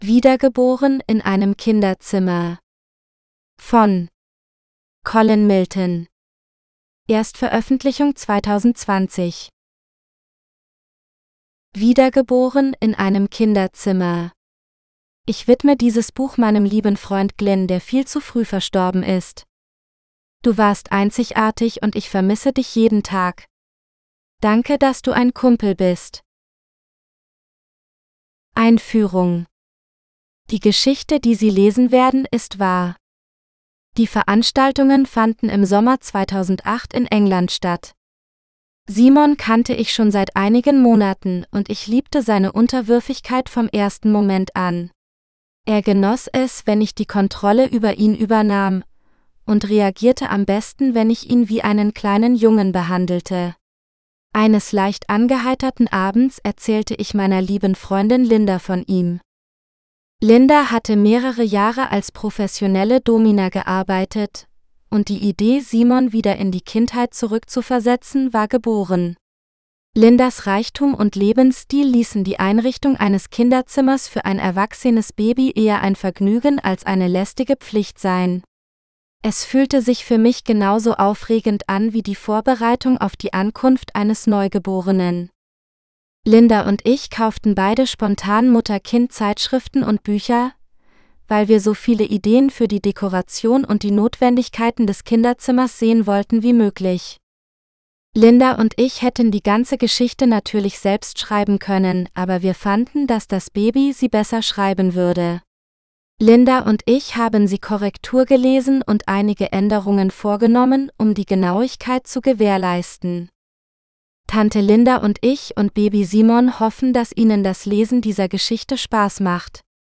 Reborn in The Nursery GERMAN (AUDIOBOOK – female): $US4.99